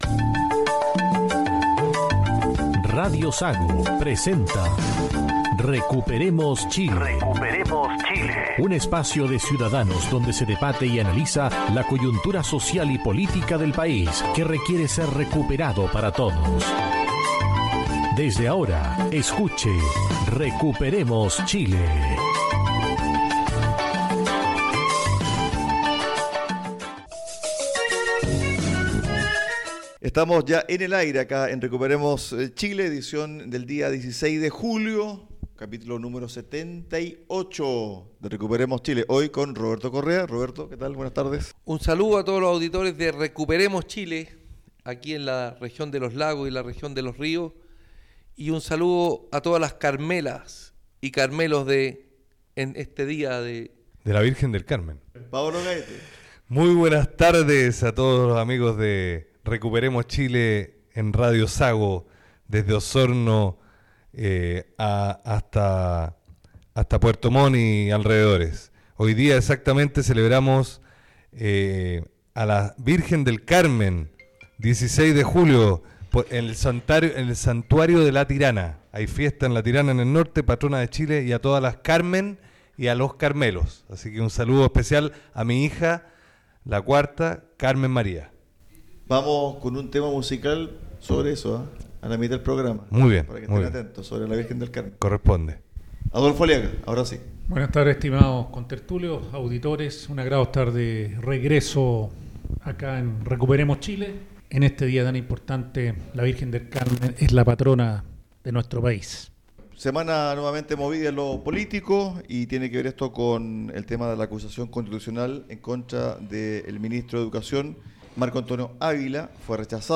En este capítulo los panelistas abordaron la fallida acusación constitucional en contra del ministro de Educación, Marco Antonio Ávila, como asimismo el blindaje a figuras políticas del Gobierno, pese a su cuestionada gestión. Además, analizaron el despilfarro de las arcas fiscales tras revelarse la entrega de miles de millones de pesos a fundaciones y proyectos «inflados» y sin impacto social.